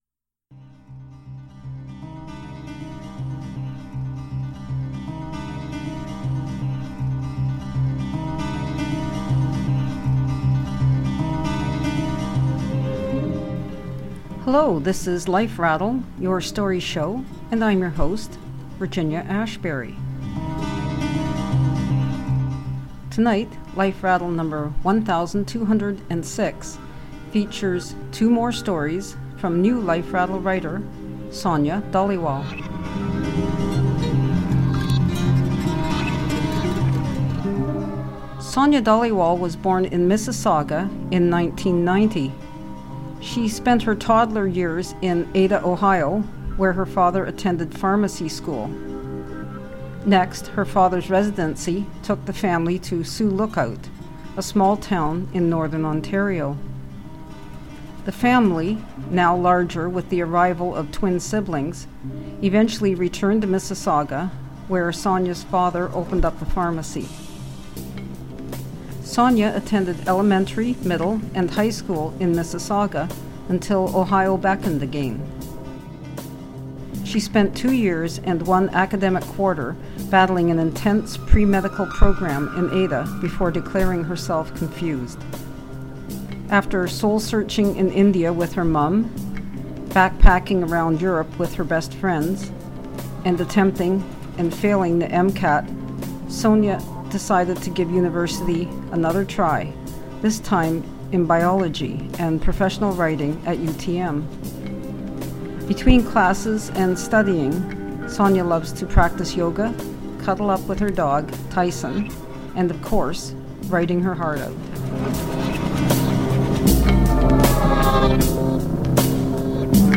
Tonight's stories contain situations and language that some listeners may find offensive.